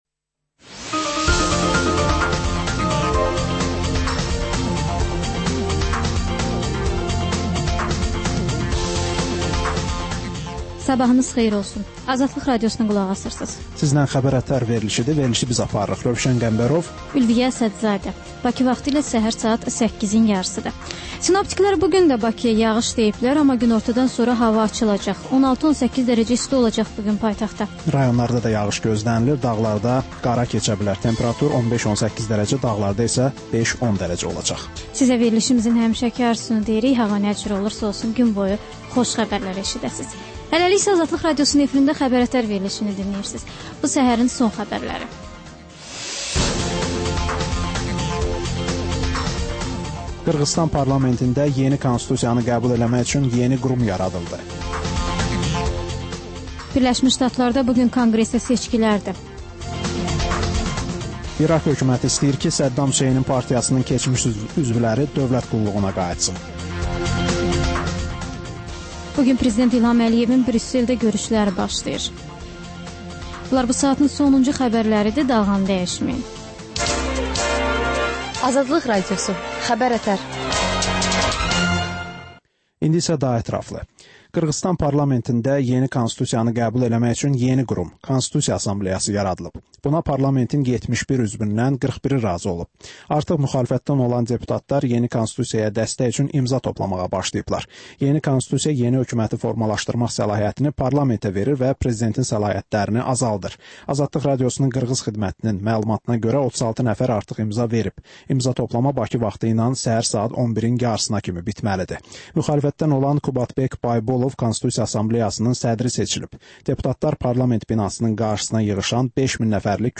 Səhər-səhər, Xəbər-ətərI Xəbər, reportaj, müsahibə